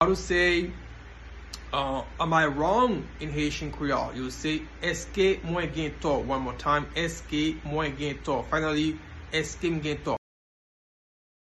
Listen to and watch “Èske mwen gen tò?” pronunciation in Haitian Creole by a native Haitian  in the video below:
Am-I-wrong-in-Haitian-Creole-Eske-mwen-gen-to-pronunciation-by-a-Haitian-teacher.mp3